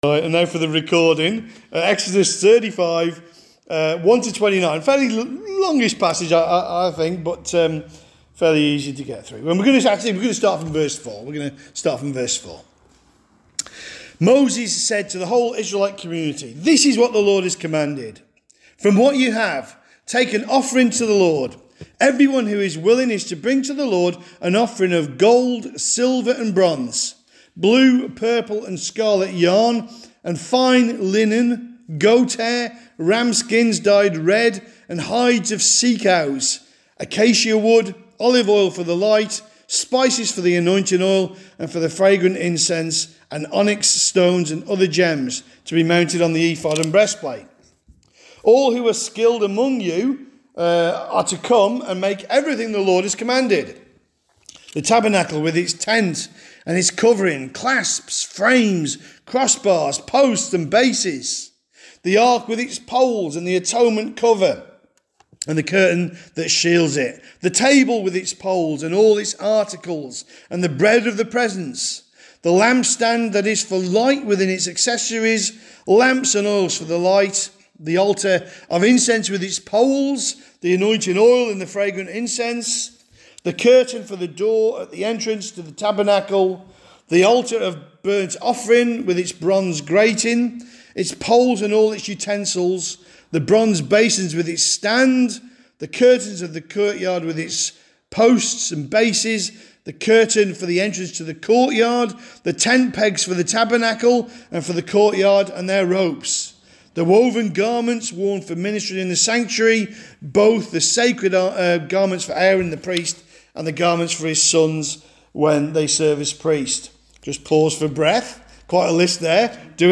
Serving Preacher